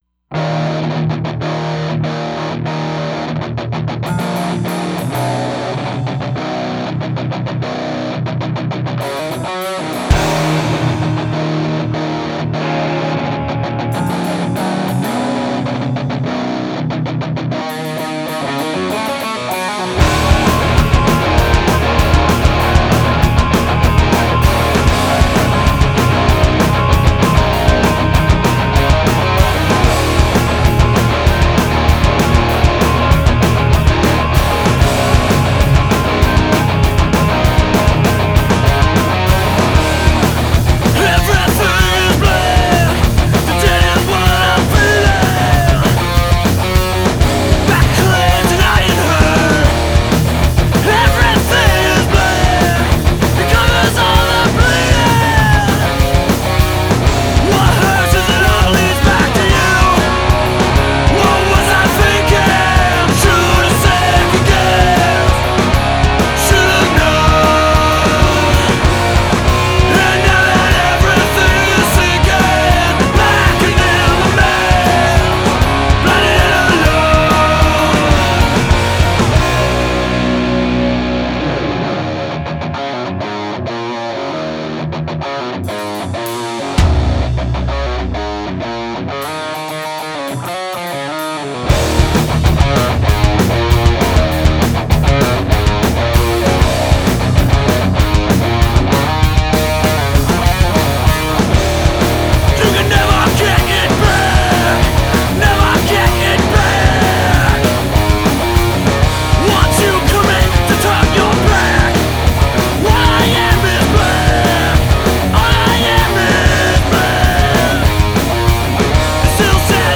catchy mood